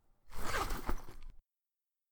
datapad_pickup.ogg